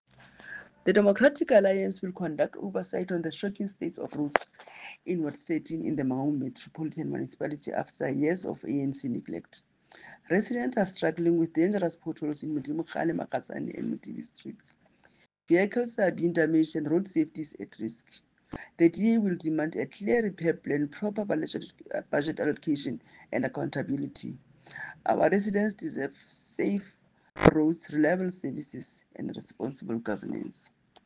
English soundbite by Cllr Mamotse Lebajoa,